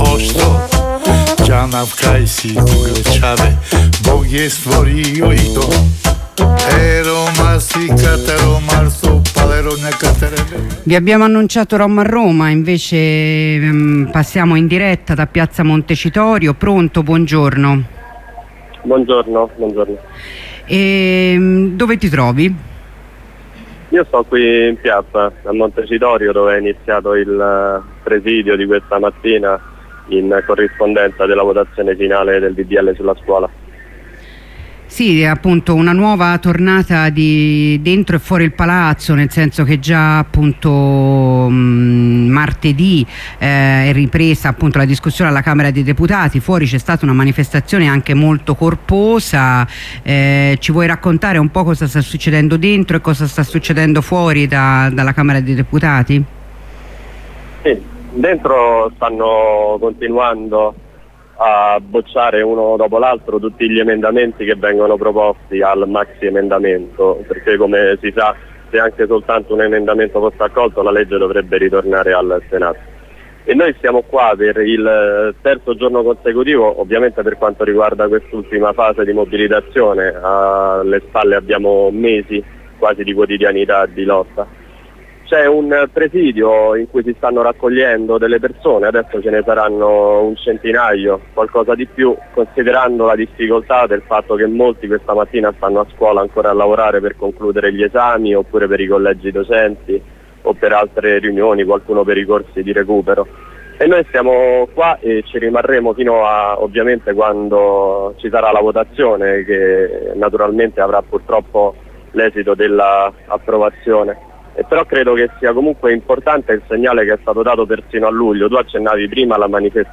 Due corrispondenze dalla piazza, la prima all'inizio del presidio, la seconda dopo l'approvazione della legge.